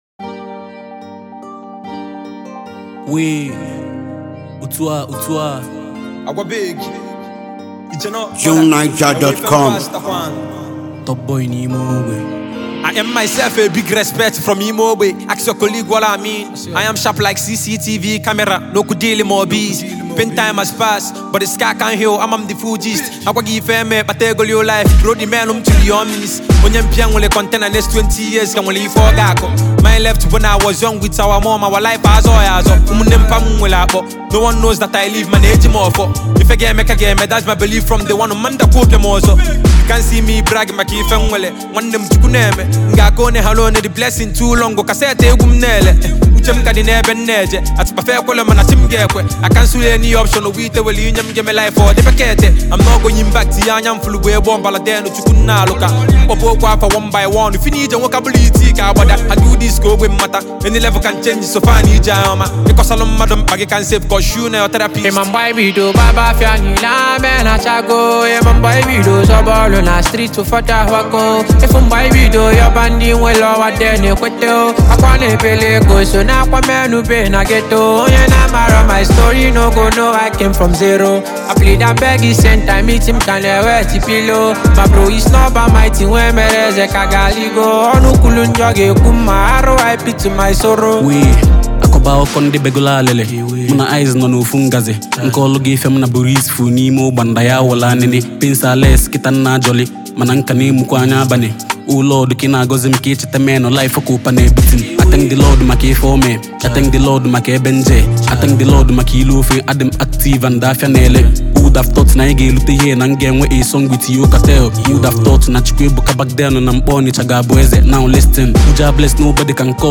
an exceptionally gifted native Nigerian rapper and poet
a brilliant Nigerian rapper, musician, and songwriter